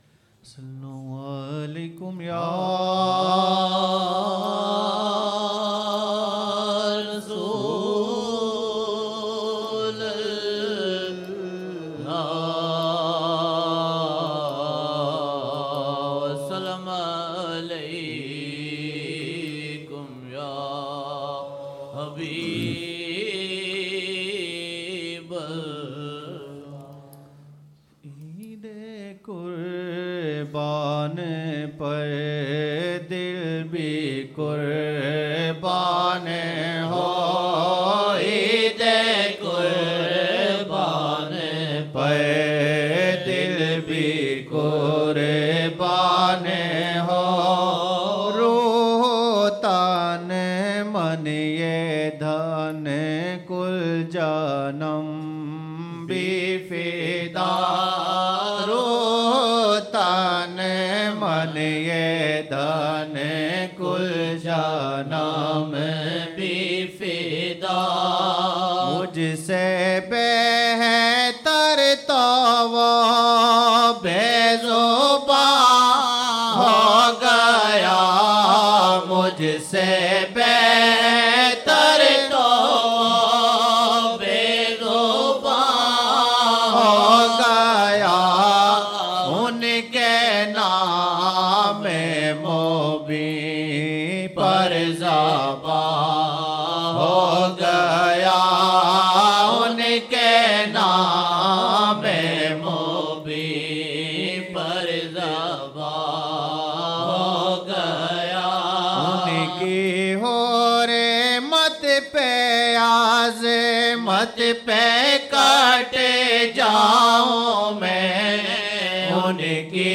Naat( Eid qarban par dil bhi qurban ho Ruh tan man dhan kul janum bhi fida) 2007-01-01 01 Jan 2007 Old Naat Shareef